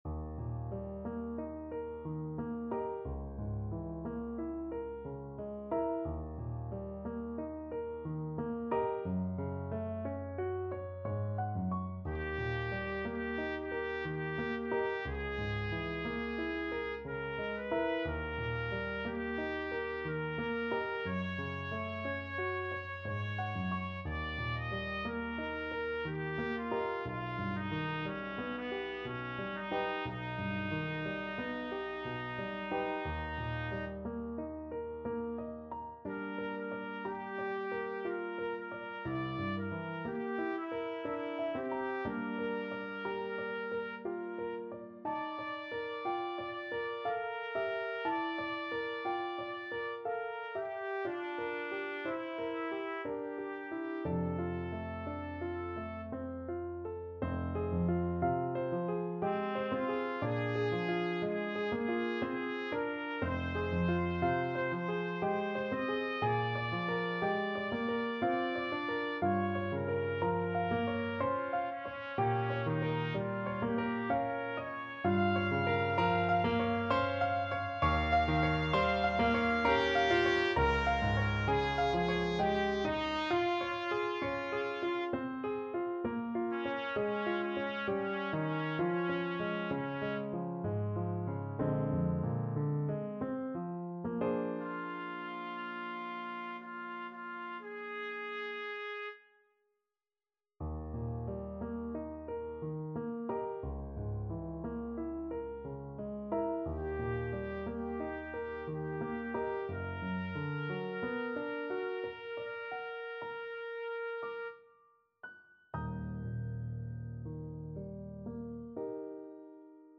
Classical Debussy, Claude Beau soir, CD 84 Trumpet version
Trumpet
Eb major (Sounding Pitch) F major (Trumpet in Bb) (View more Eb major Music for Trumpet )
Andante ma non troppo =60
3/4 (View more 3/4 Music)
Classical (View more Classical Trumpet Music)